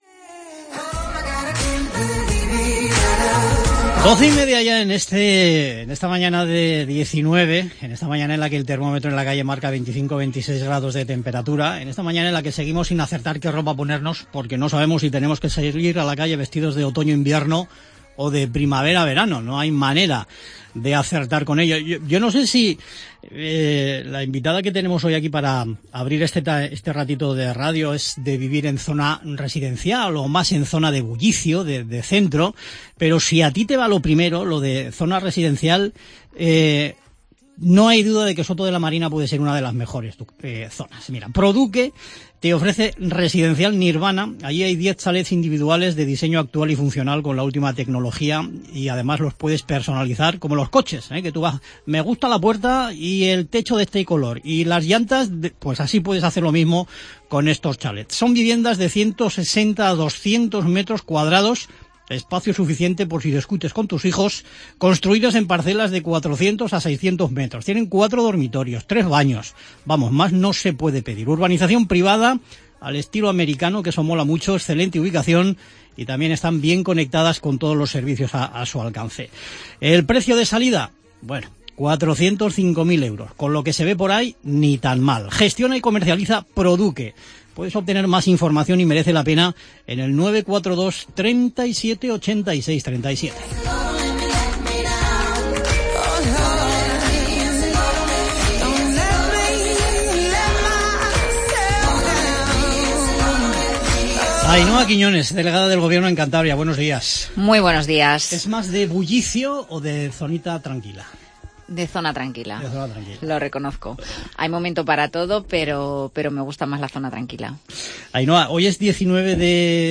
Ainoa Quiñones, Delegada del Gobierno en Cantabria, ha dejado clara en esta entrevista su interés por ser candidata del PSOE a la alcaldía de Santander en las elecciones municipales del próximo mes de mayo.